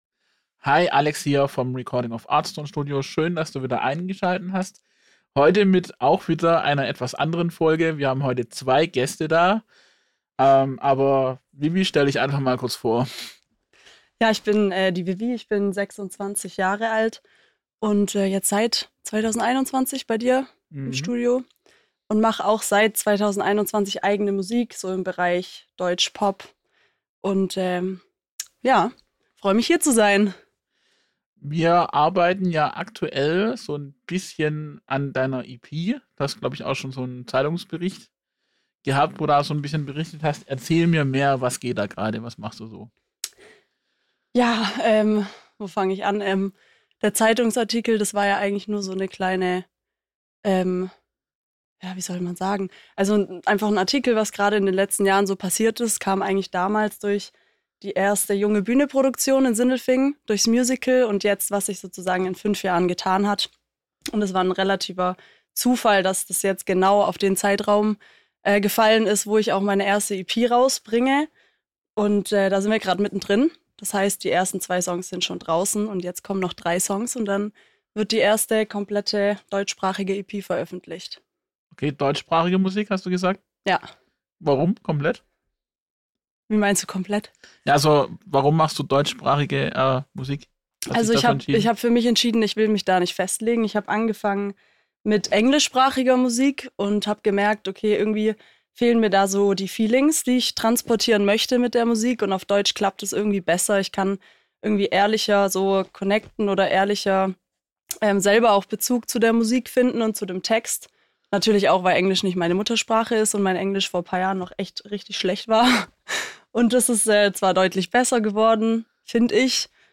Ein Gespräch über Haltung, Entwicklung, Zusammenarbeit – und den Mut, Musik zu machen, die nicht jedem gefallen muss, aber dir selbst etwas bedeutet.